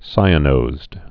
(sīə-nōzd, -nōsd)